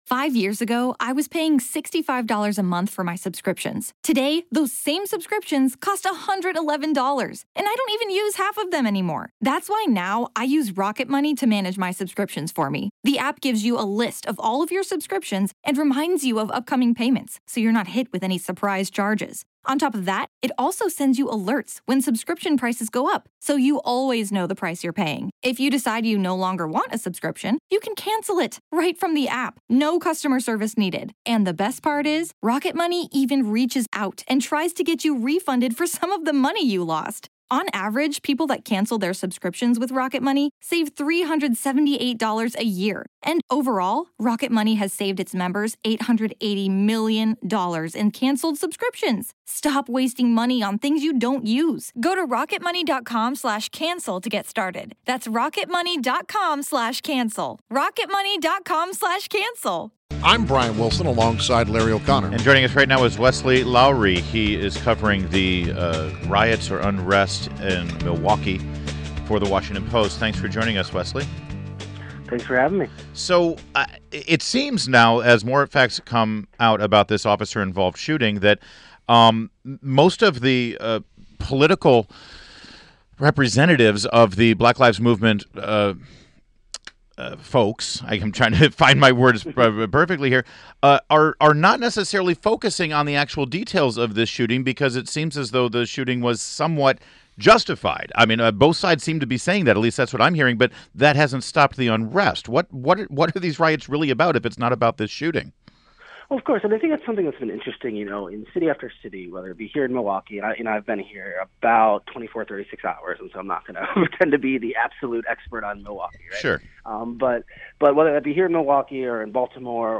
WMAL Interview - WESLEY LOWERY - 08.16.16